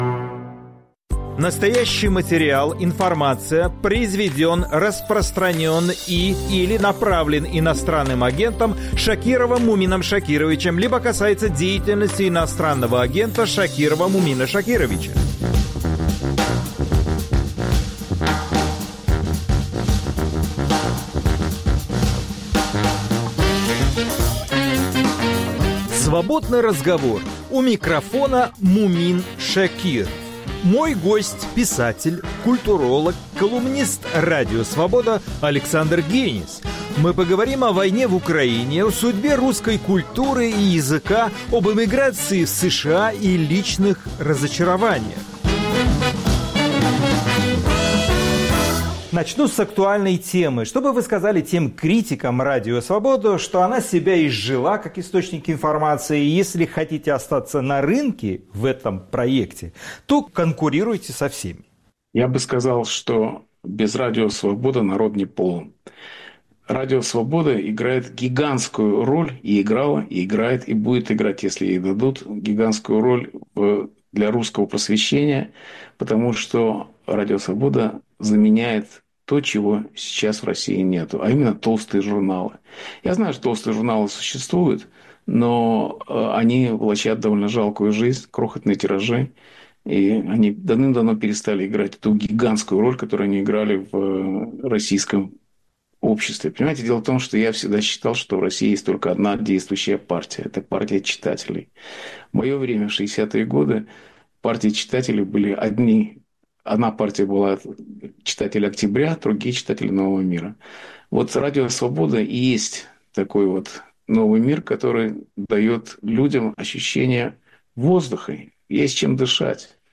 Свободный разговор с писателем Александром Генисом